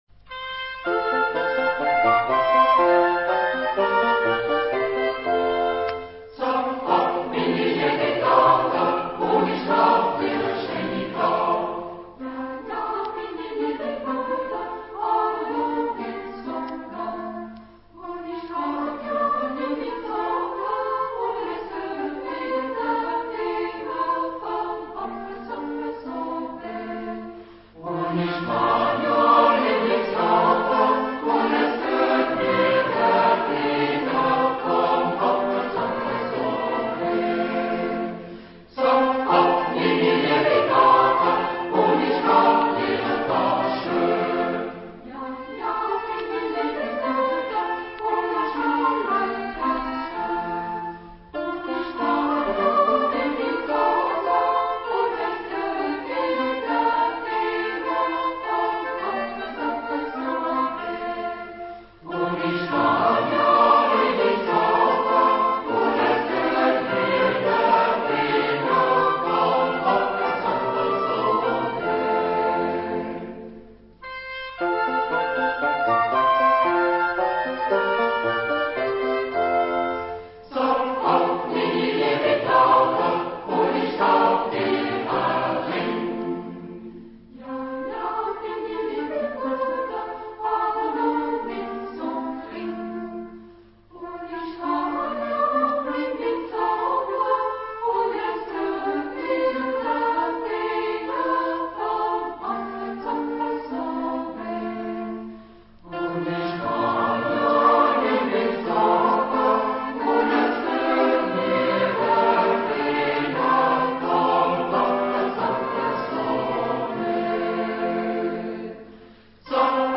Genre-Style-Form: Popular ; Secular
Type of Choir: SATB  (4 mixed voices )
Tonality: F major
Origin: Alsace (France)